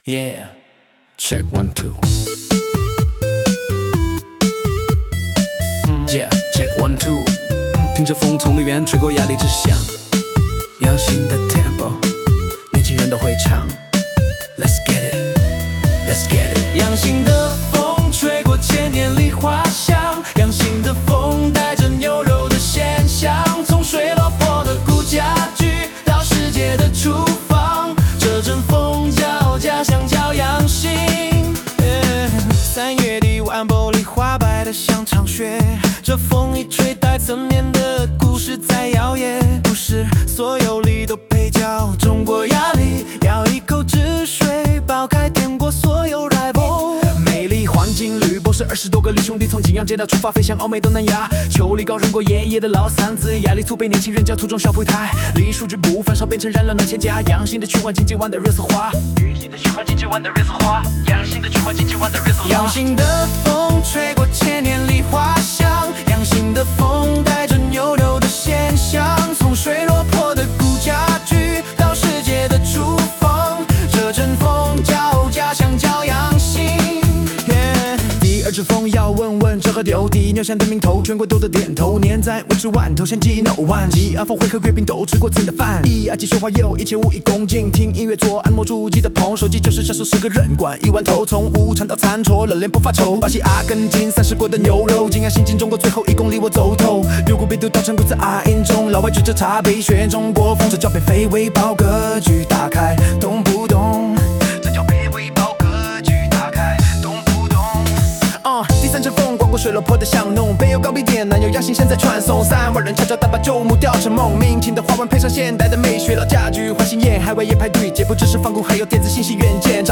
采样建议：梨花飘落声+牛铃声+锯木头声
BPM: 140
风格：Trap + 民乐元素（建议加二胡或笛子loop）